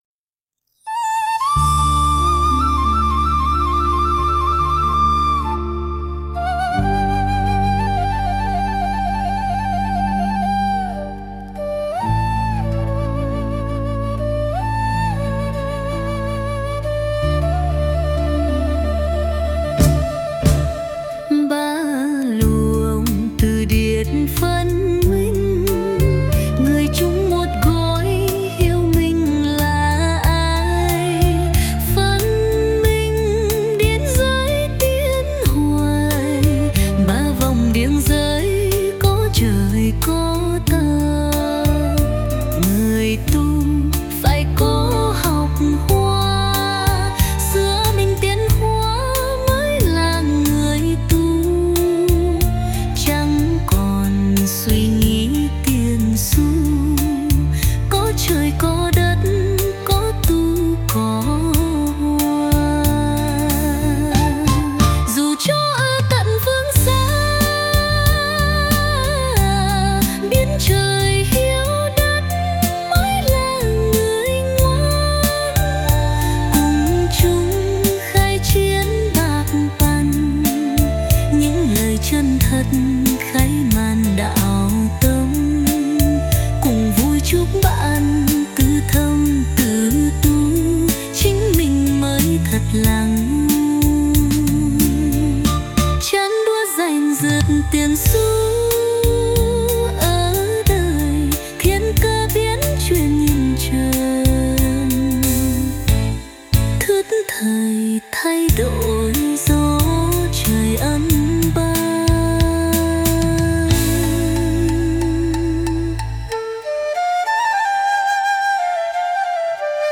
124-Co-thien-dia-nhan-03-nu-cao.mp3